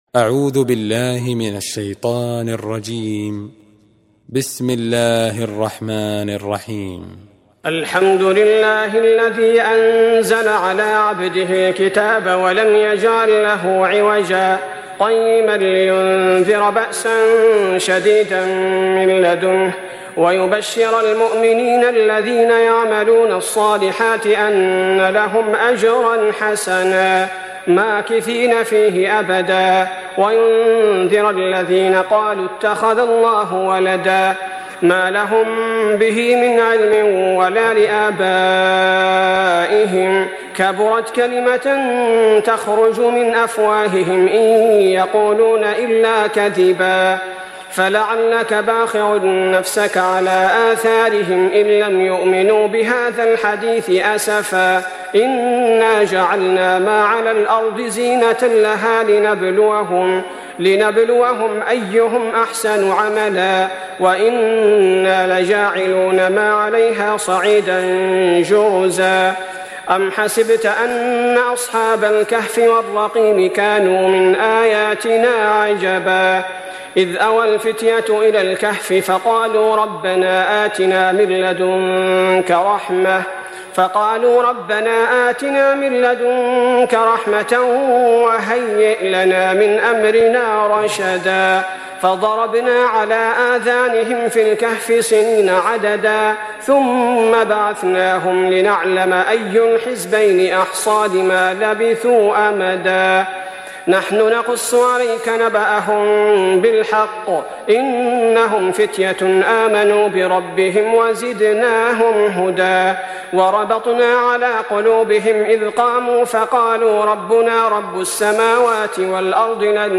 دانلود سوره الكهف mp3 عبد الباري الثبيتي روایت حفص از عاصم, قرآن را دانلود کنید و گوش کن mp3 ، لینک مستقیم کامل